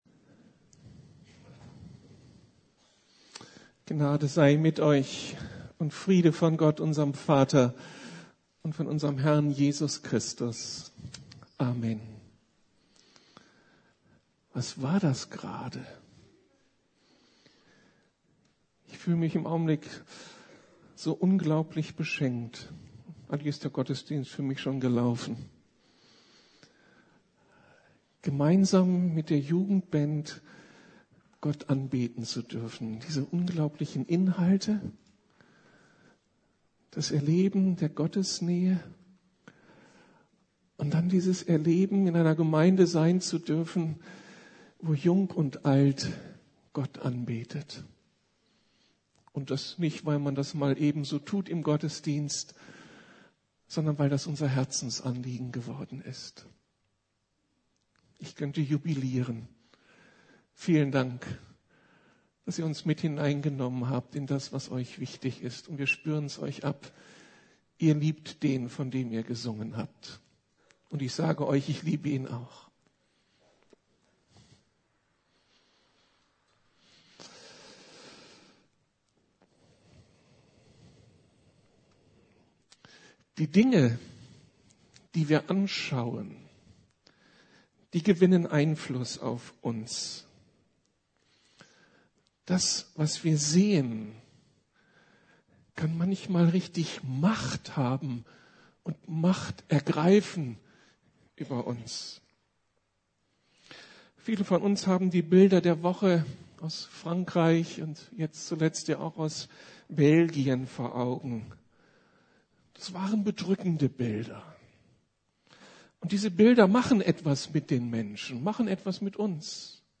Ihr habt doch Augen- fangt an zu sehen! ~ Predigten der LUKAS GEMEINDE Podcast